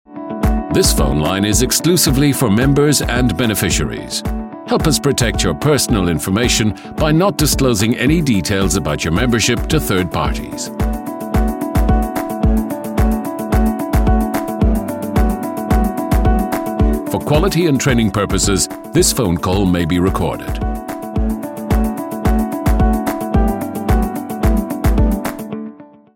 Englisch (Irland)
Tief, Natürlich, Zuverlässig, Freundlich, Warm
Unternehmensvideo